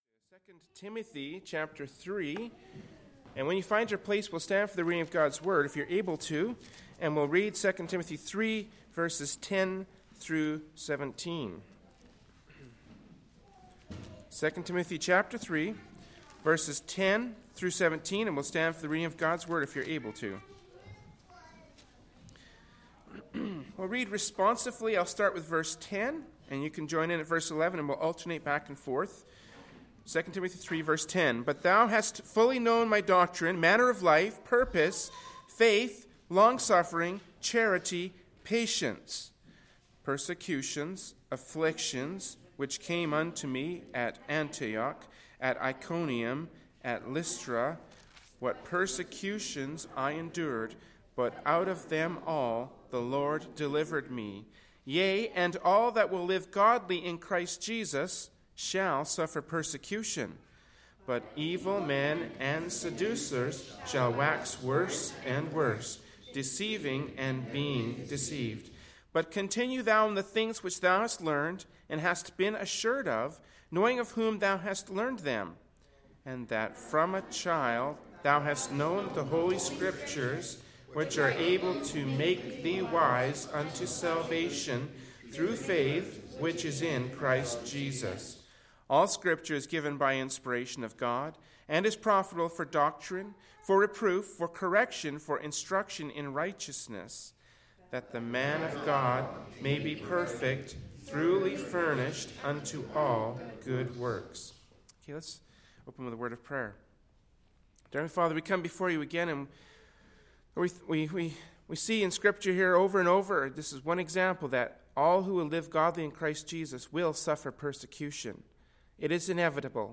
“2 Timothy 3:10-17” from Sunday Morning Worship Service by Berean Baptist Church.
Genre: Preaching.